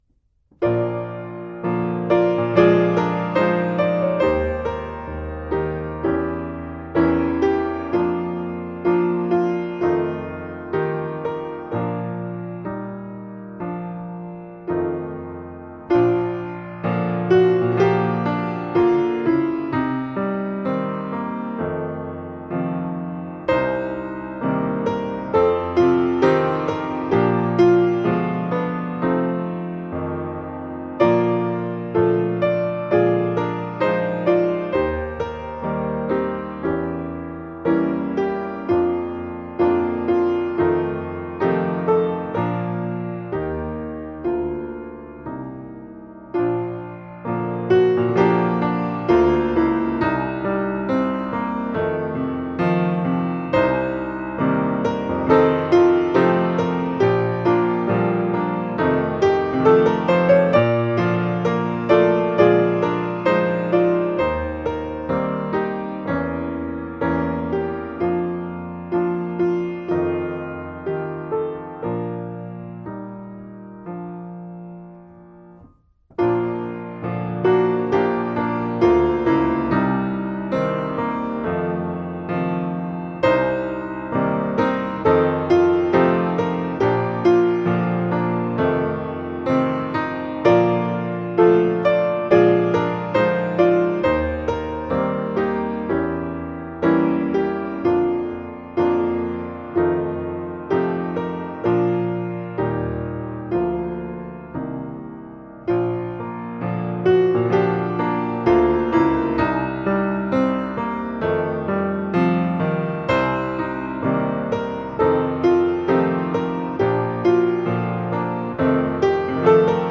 - UMH CD.....UDM - DM Accompaniment.....
Hymns Considered For this week: